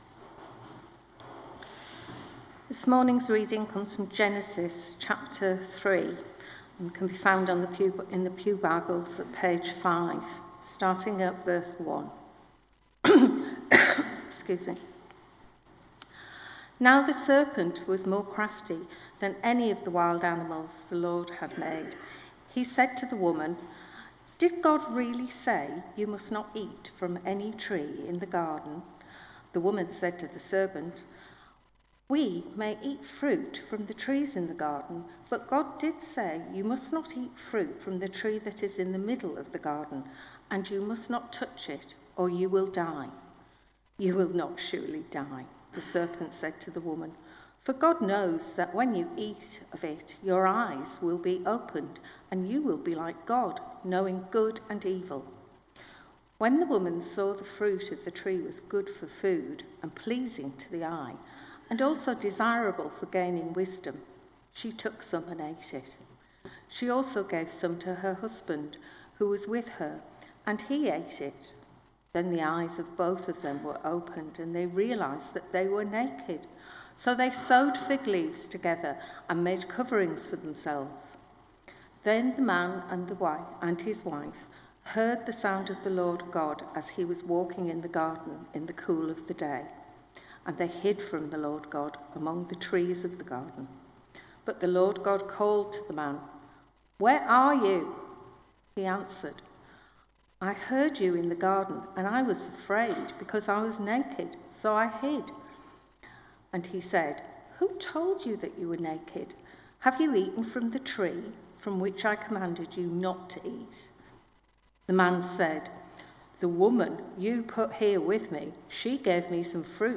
24 Tagged with Morning Service Audio